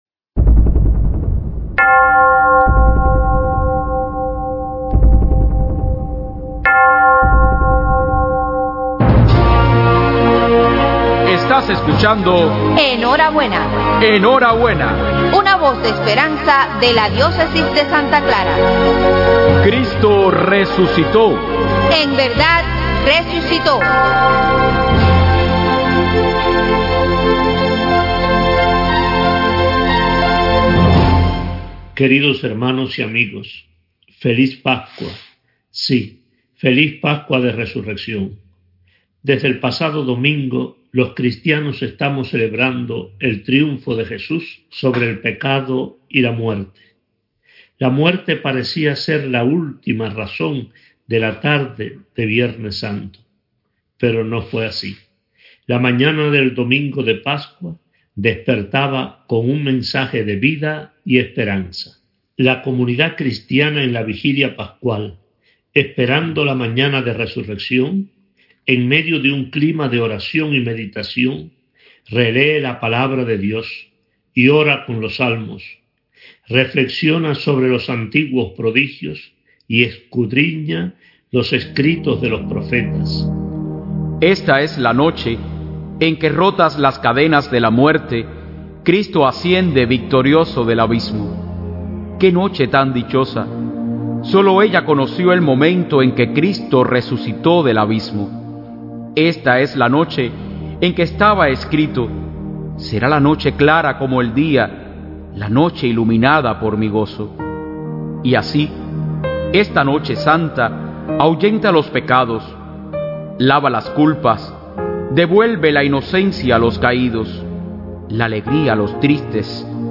II DOMINGO DE PASCUA: MENSAJE RADIAL DE MONS. ARTURO GONZÁLEZ AMADOR, OBISPO DE SANTA CLARA